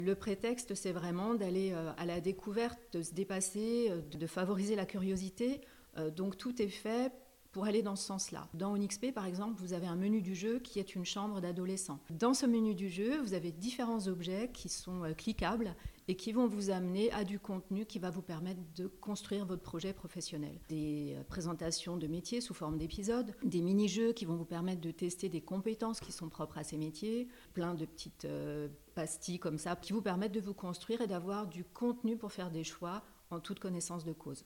Des interviews sur les ondes de Chérie FM et ICI Belfort-Montbéliard ont permis de donner la parole à sa créatrice